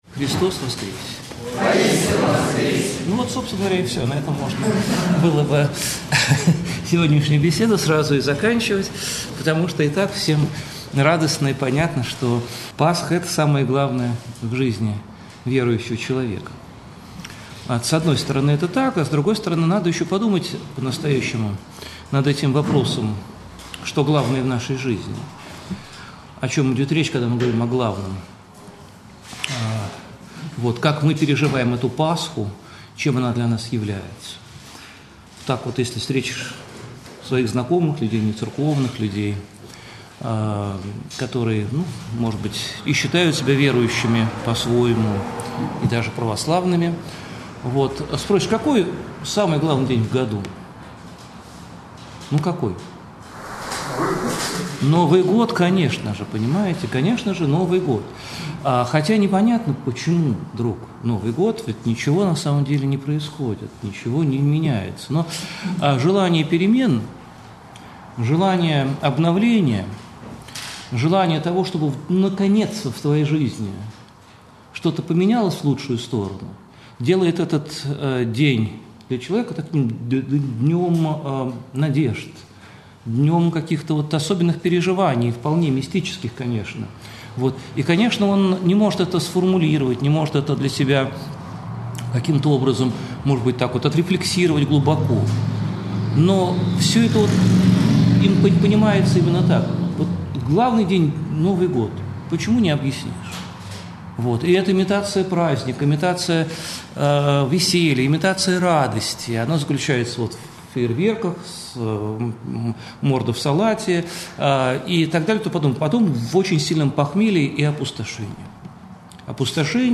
Аудиозапись mp3 видео лекция Протоиерей Алексий Уминский-Воскресение Христово и пасхальная радость в жизни христианина (видео)